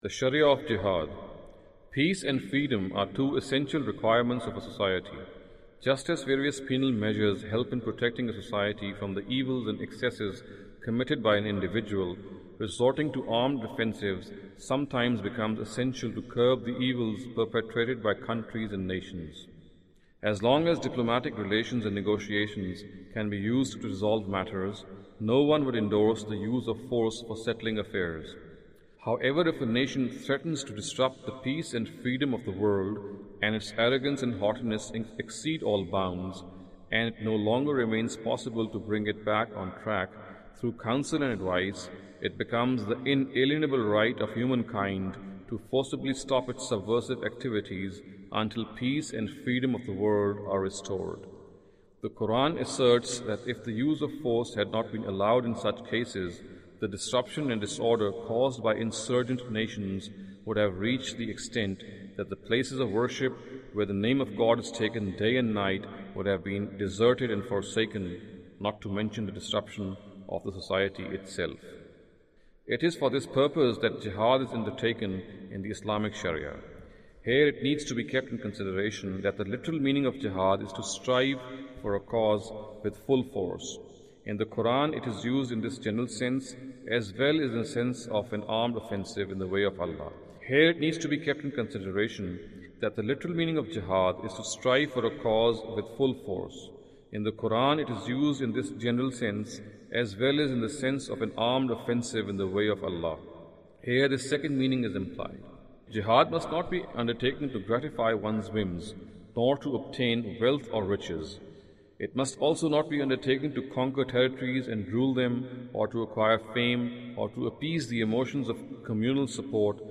Audio Books
Audio book of English translation of Javed Ahmad Ghamidi's book "Islam a Concise Intro".